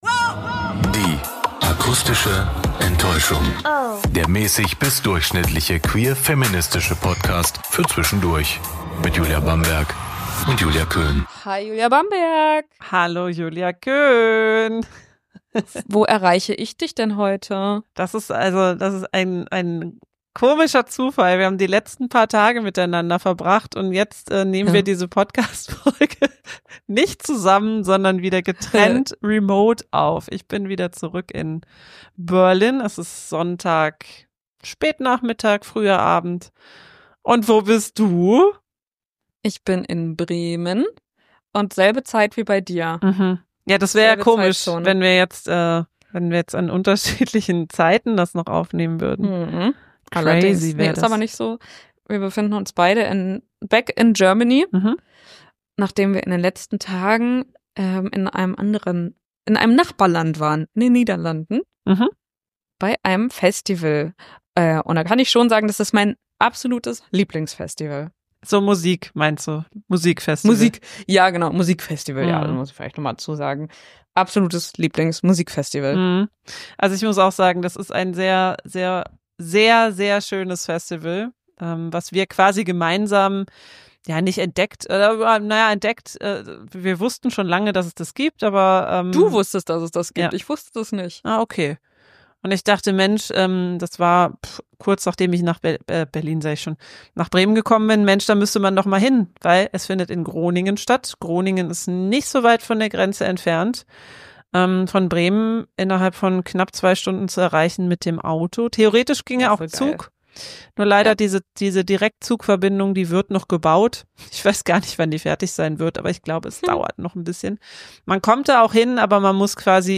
Der queer-feministische Talk